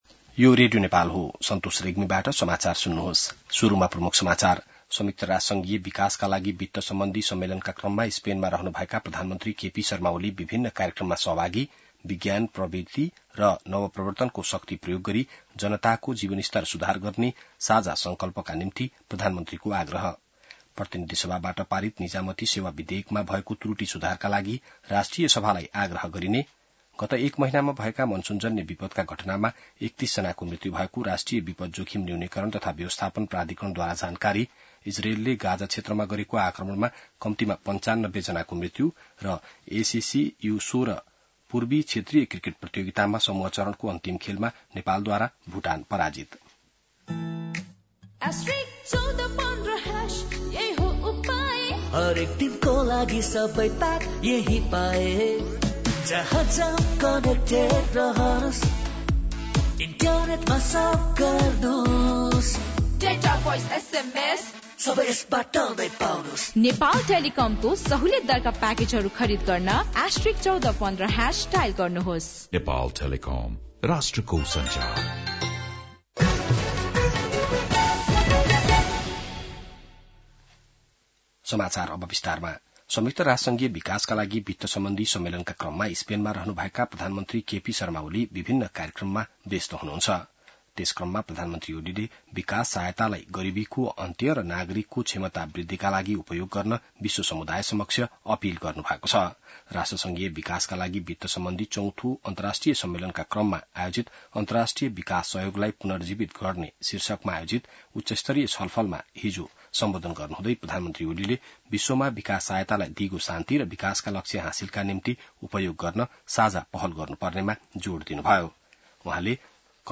बिहान ७ बजेको नेपाली समाचार : १८ असार , २०८२